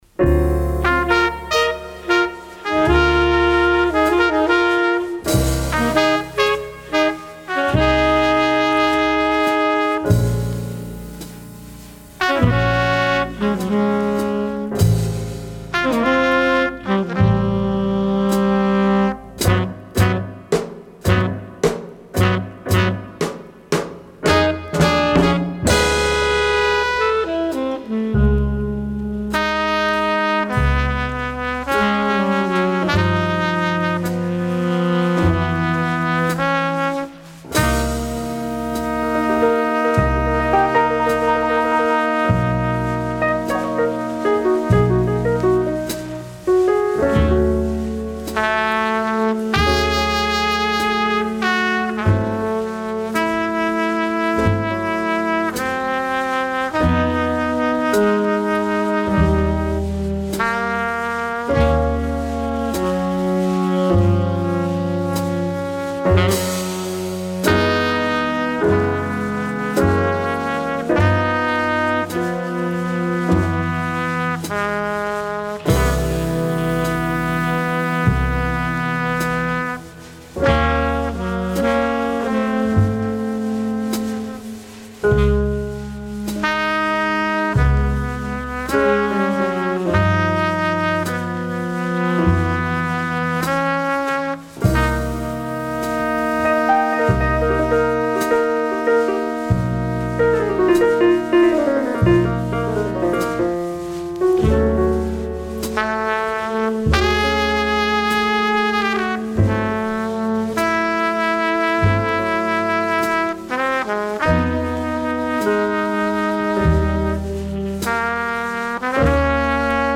Original 1959 mono pressing